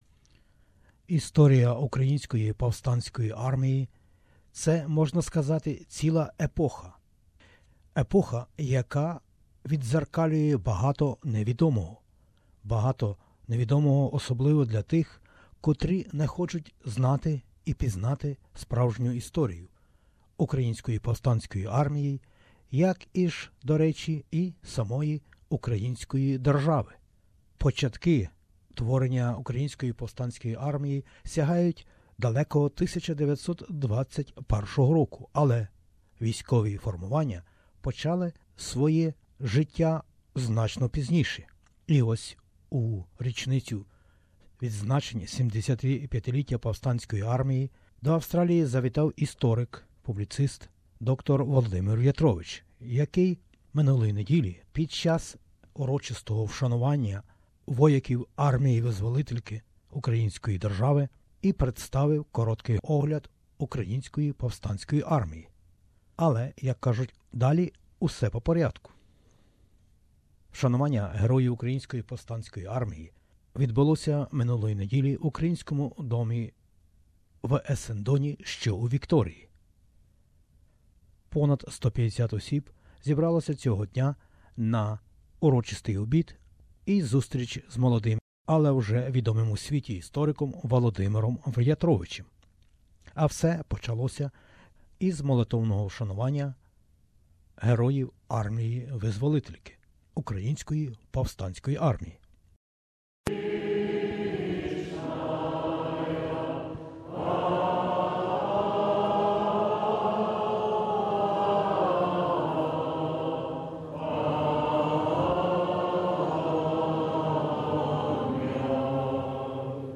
Around the world, Ukrainians are observing the 75th anniversary of the founding of the Ukrainian Insurgent Army (UPA), the legendary fighters of the 1940s and 1950s who bravely fought for the freedom of Ukraine. On June 25, 2017 the Ukrainian Australian community in Victoria commemorated the 75th anniversary of UPA in Melbourne at Ukrainian House, Essendon.